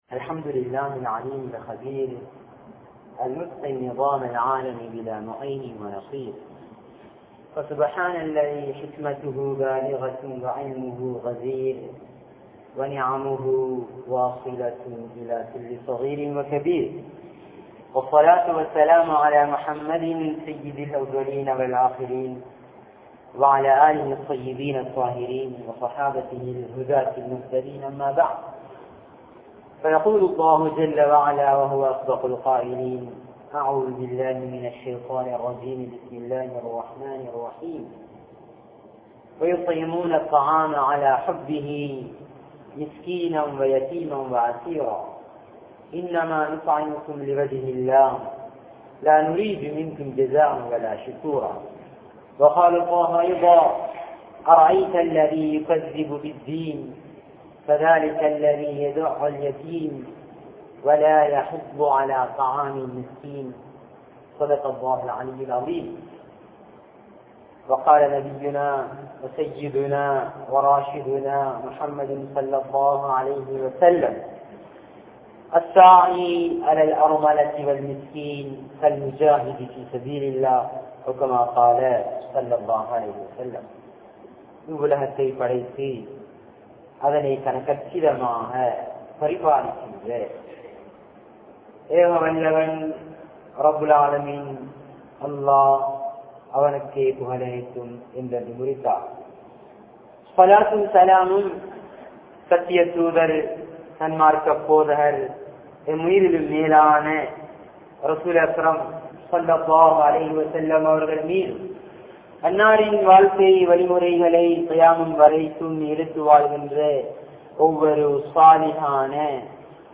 Pirarin Kastangalil Pangukolvoam (பிறரின் கஷ்டங்களில் பங்கு கொள்வோம்) | Audio Bayans | All Ceylon Muslim Youth Community | Addalaichenai
Majmaulkareeb Jumuah Masjith